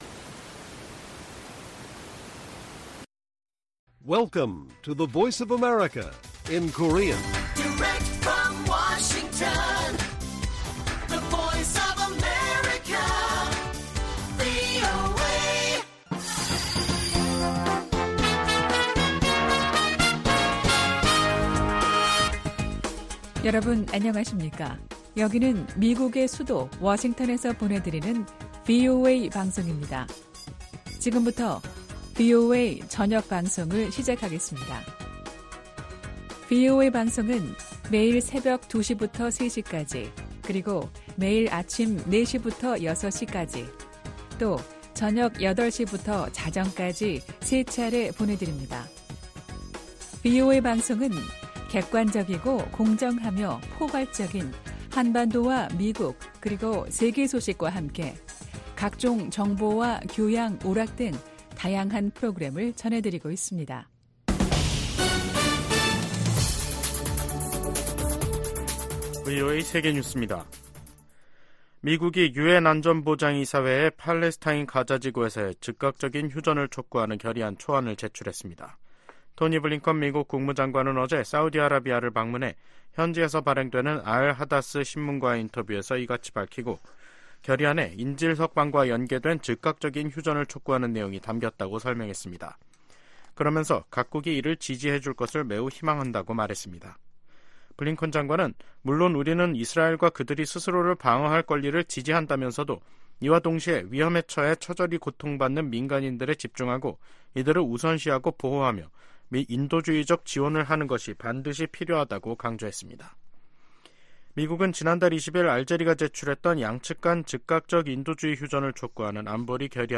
VOA 한국어 간판 뉴스 프로그램 '뉴스 투데이', 2024년 3월 21일 1부 방송입니다. 북한이 영변 핵시설에서 핵탄두 소형화에 필수적인 삼중수소 생산 시설을 가동 중이라는 위성사진 분석 결과가 나왔습니다. 북한이 러시아와 관계를 강화하면서 국제 질서를 위협하고 있다고 주한미군사령관이 지적했습니다. 블라디미르 푸틴 러시아 대통령의 5연임이 확정되면서 북한과 러시아 밀착에 탄력이 붙을 것이란 전망이 나옵니다.